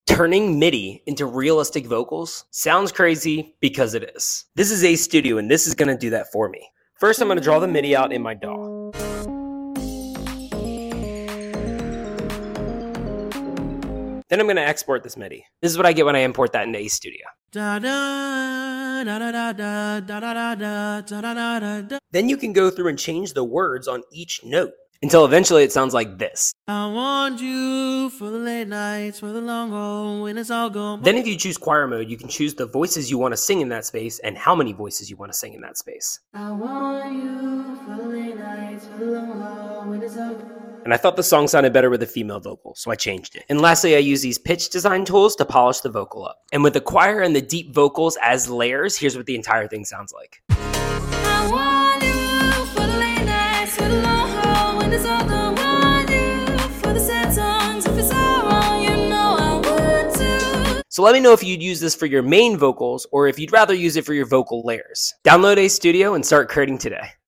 Turning MIDI into realistic vocals? sound effects free download